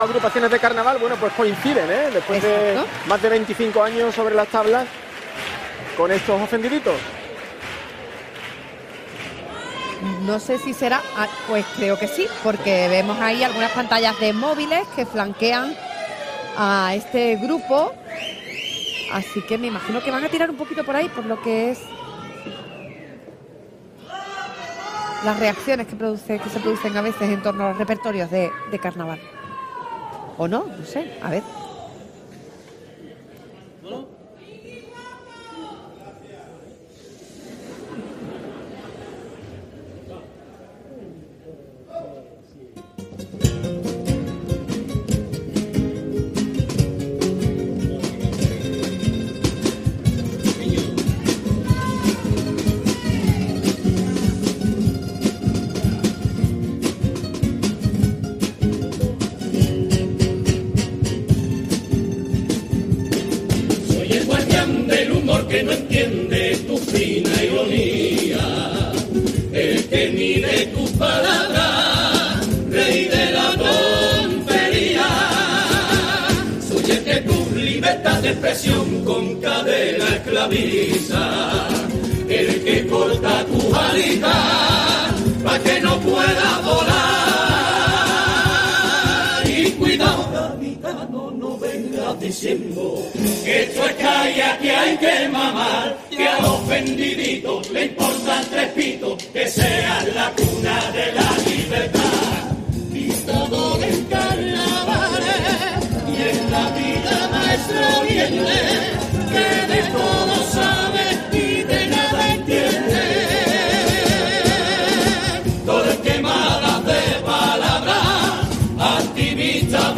Escucha la actuación de la Comparsa Los ofendiditos en la fase preliminares del COAC Carnaval de Cádiz 2024.